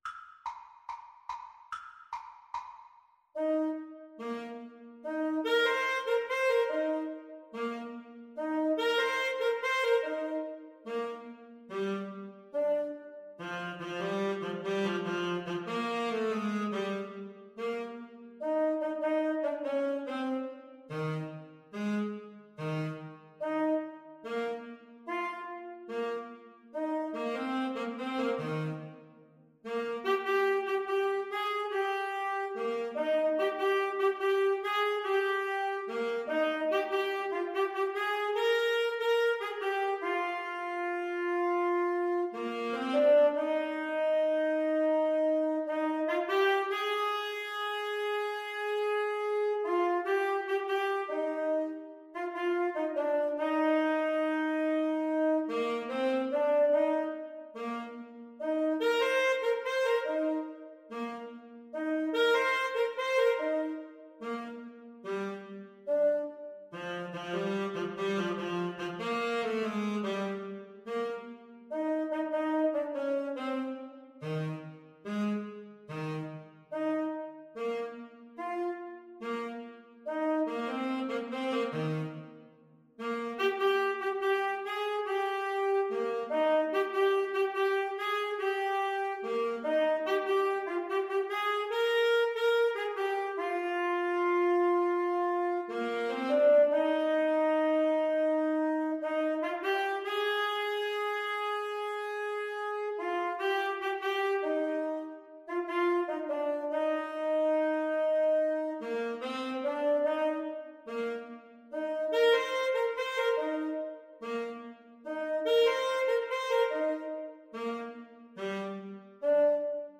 Alto SaxophoneTenor Saxophone
Gentle two in a bar = c. 72